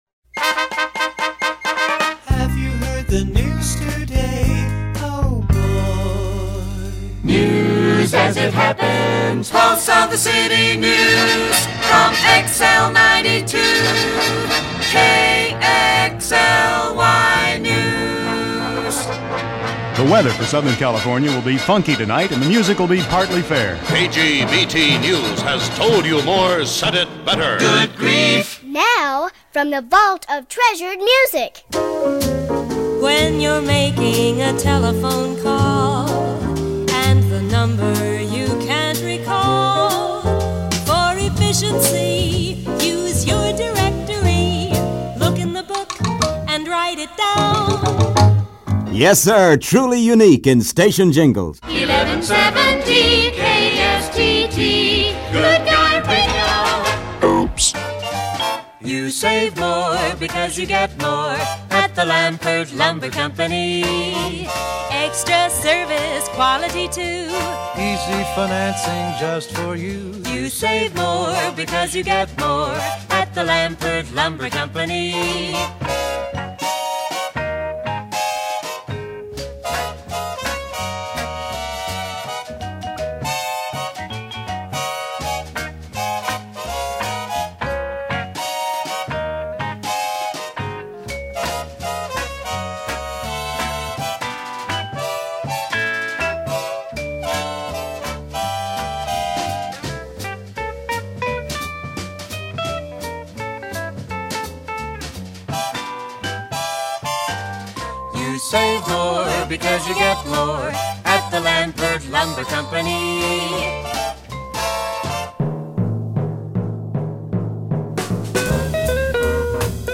Newsbreak, more stereo, a capellas